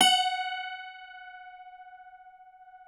53q-pno16-F3.aif